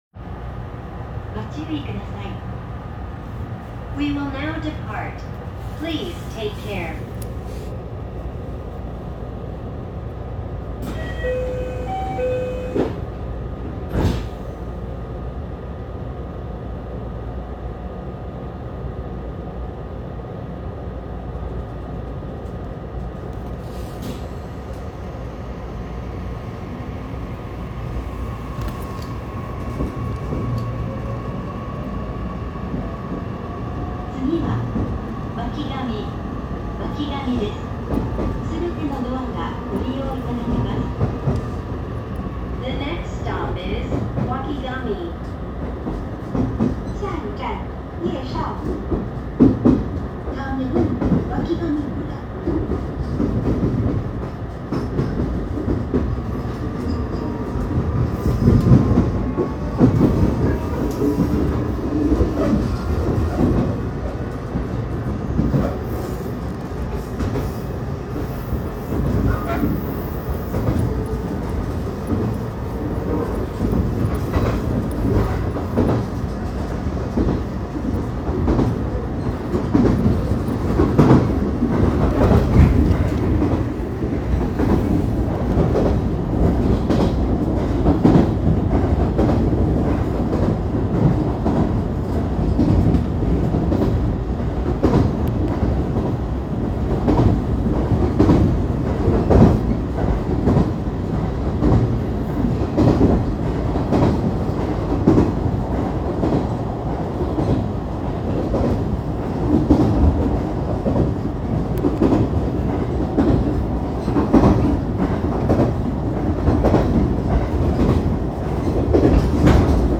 ・227系1000番台走行音
【和歌山線】吉野口→掖上（4分47秒：1.71MB）
1000番台のモーターは東芝SiCとなりました。とはいえ、それ程変わった音ではなく言われなければただのIGBTに聞こえてしまうかも…。